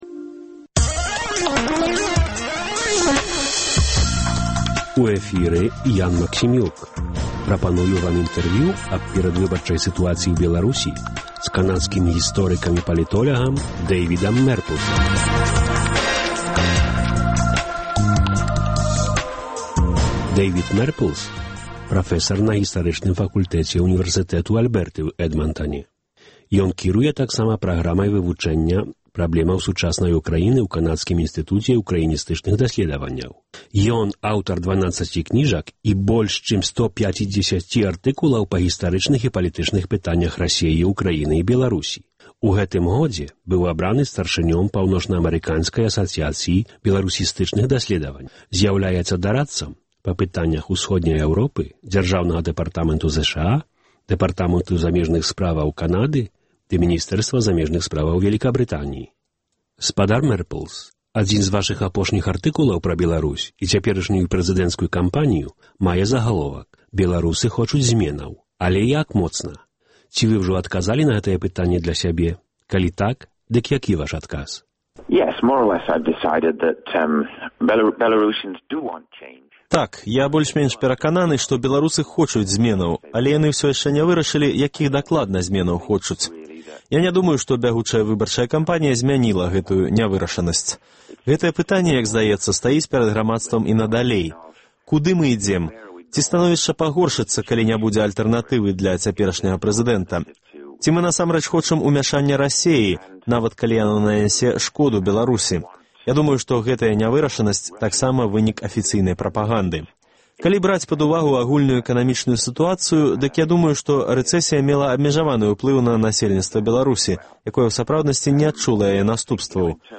Сэрыя гутарак з жонкамі і нявестамі кандыдатаў у прэзыдэнты.